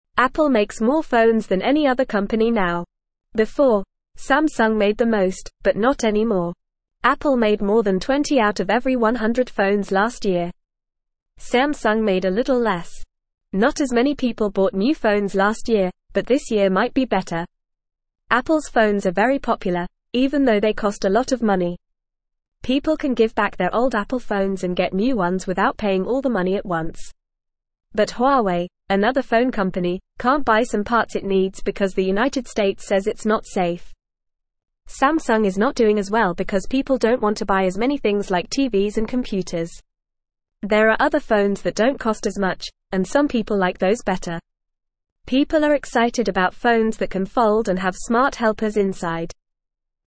Fast
English-Newsroom-Beginner-FAST-Reading-Apple-Makes-the-Most-Phones-Samsung-Not-Doing-Well.mp3